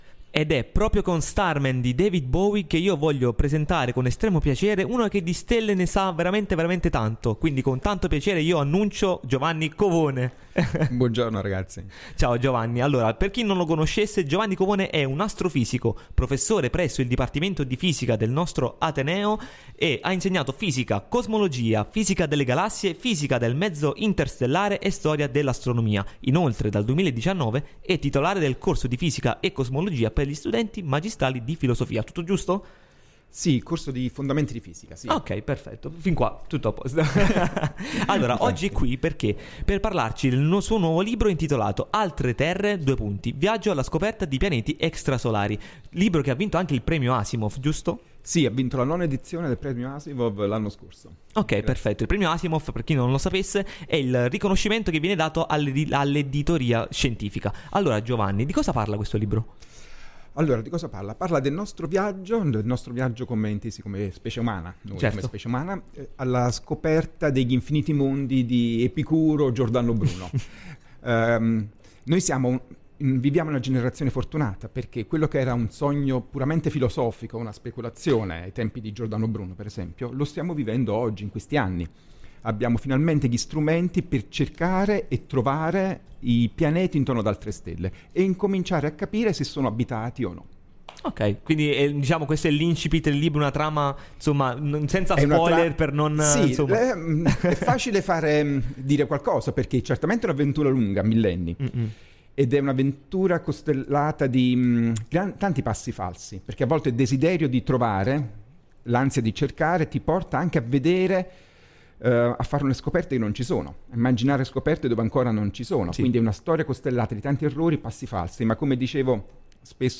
Intervista al dj